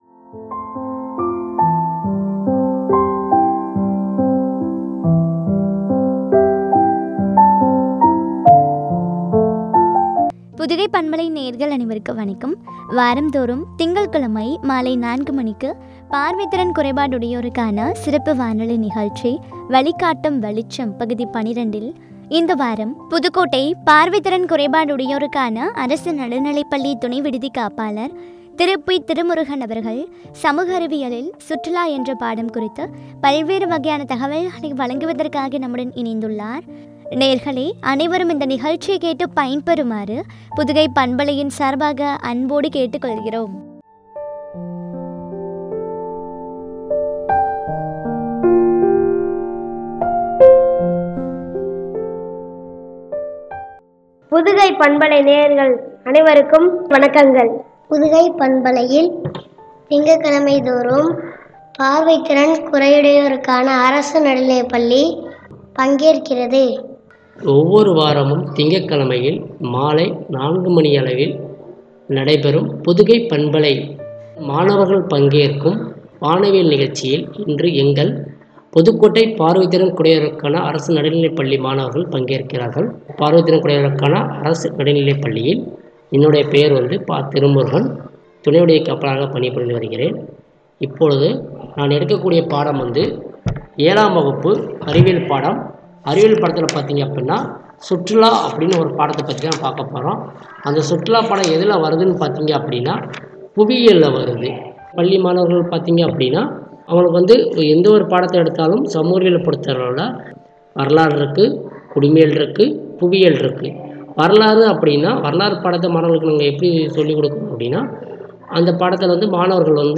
சமூக அறிவியல் பாடம் ” சுற்றுலா” குறித்து வழங்கிய உரையாடல்.